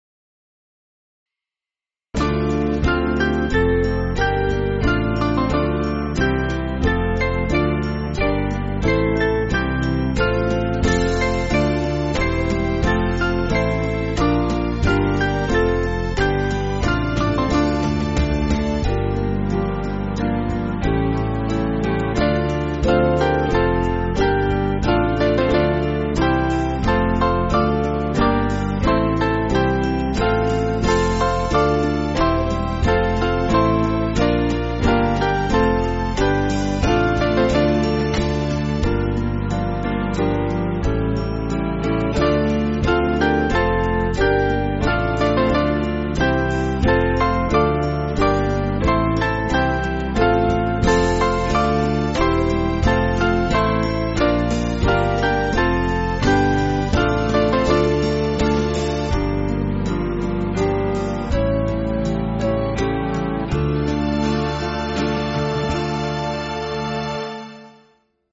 Small Band
(CM)   3/Dm